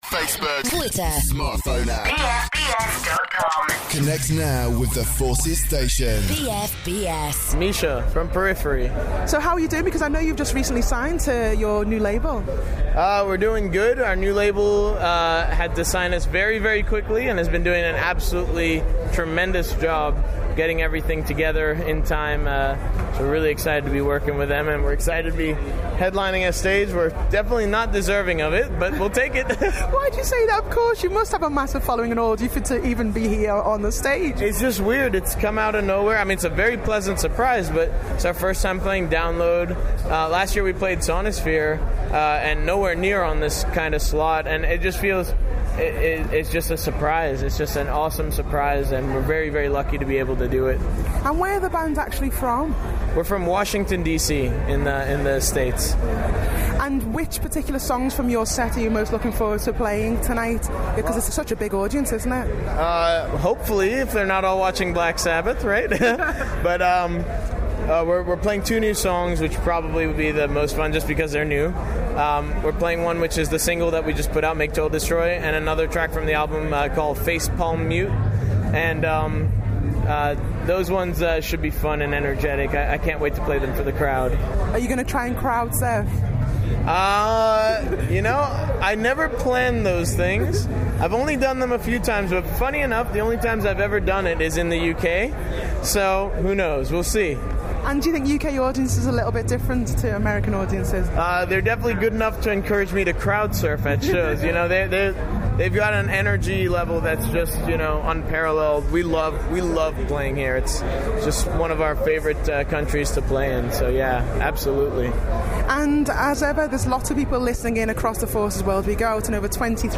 One Month On - has the mud settled yet? At Download 2012